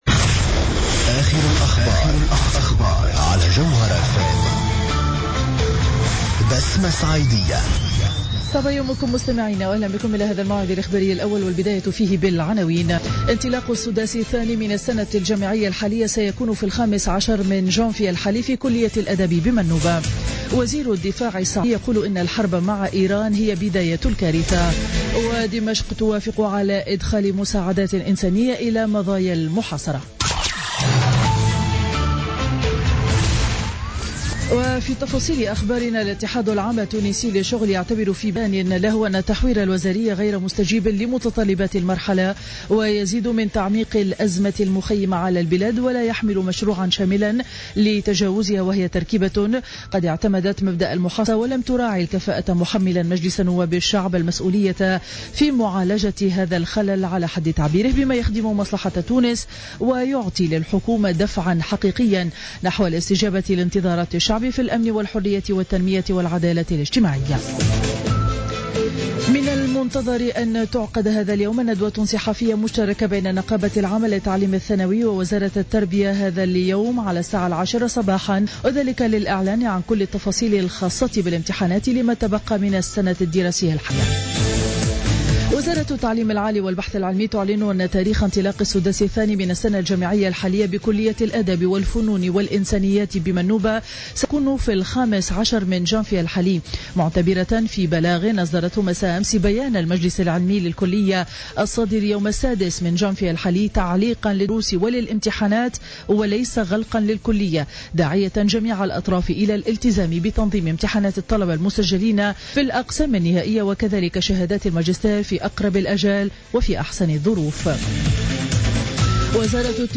Journal Info 07h00 du vendredi 8 janvier 2016